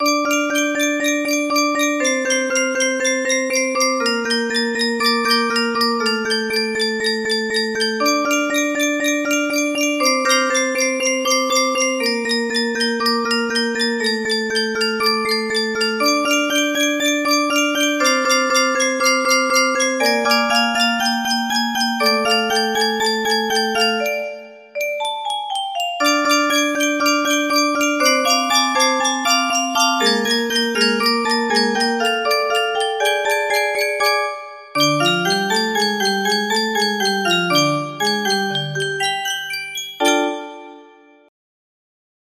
my creation music box melody
Full range 60